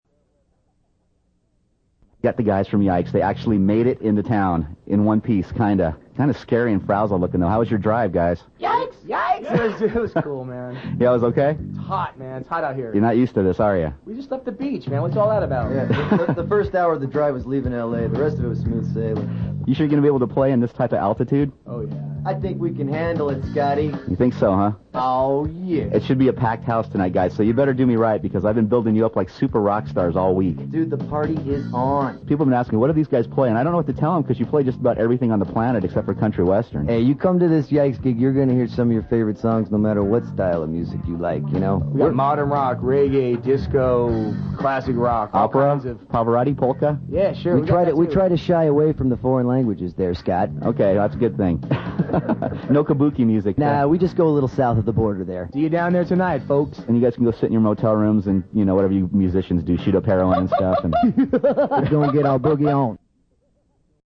More is being added all the time Check out this video of a "Girl Gone Wild" after a YikesGig yikes YIKES Yikes Check Out this Radio Interview with the Band
band_interview_yikes.mp3